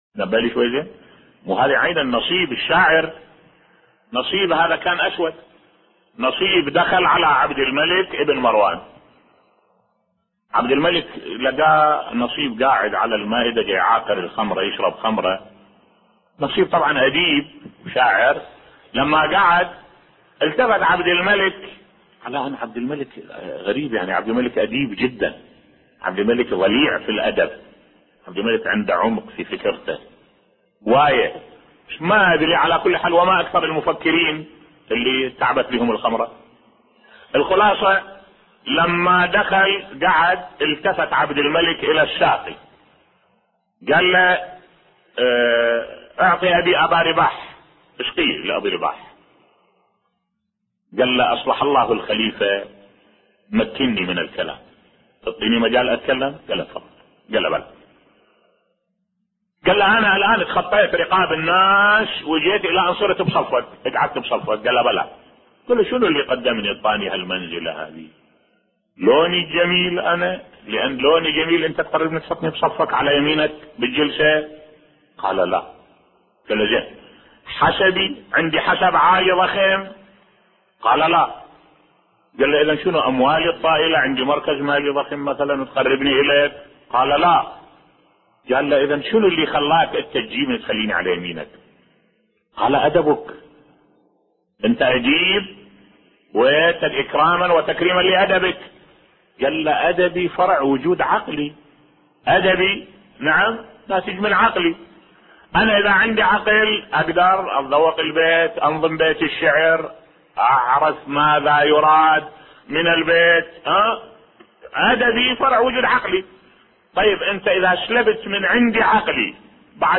ملف صوتی قصة عبدالملك بن مروان مع الشاعر ابن ابي رباح بصوت الشيخ الدكتور أحمد الوائلي